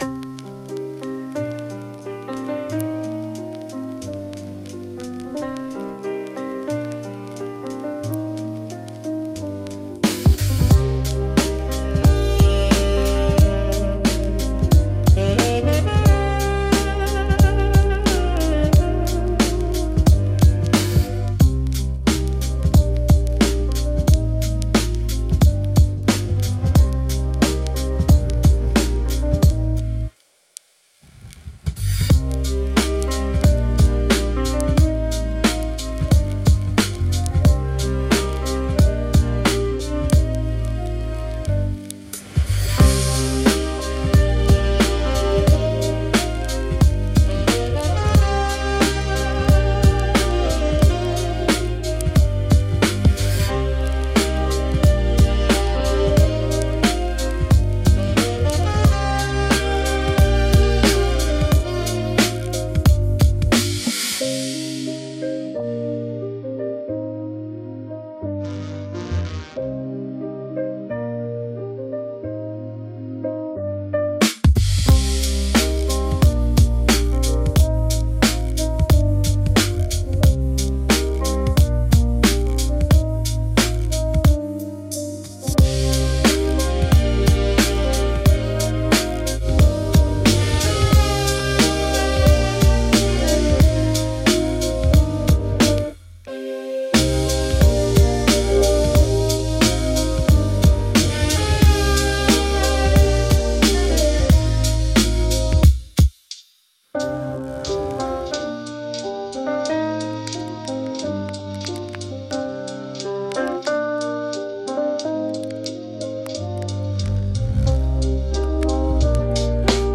inspirations 90 BPM – Do mineur